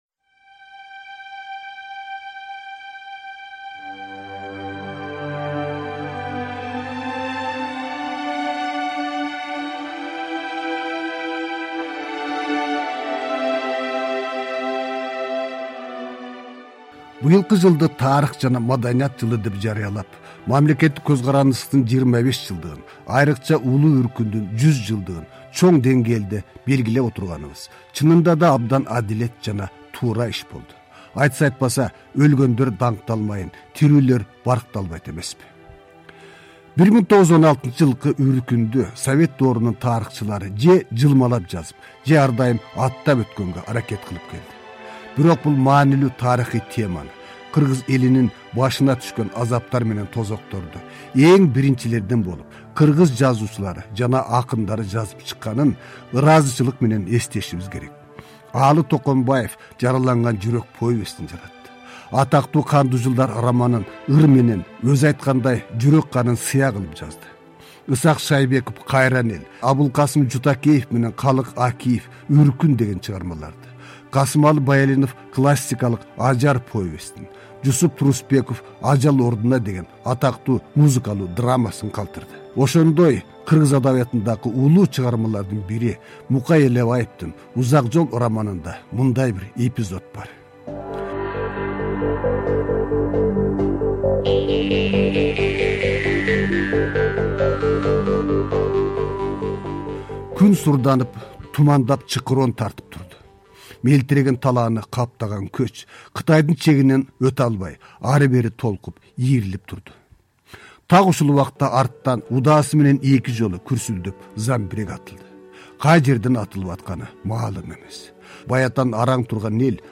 «Тарых жана тагдыр» түрмөгүнүн бул жолку адабий-музыкалуу уктуруусу Улуу Үркүндүн 100 жылдыгына арналат. Эки бөлүктөн турган бул уктуруунун экинчи бөлүгүн сунуштайбыз.